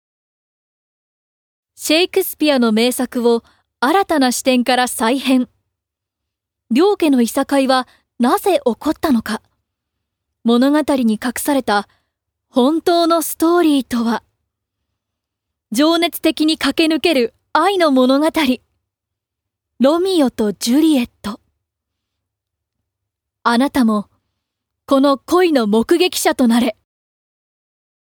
◆映画予告編ナレーション◆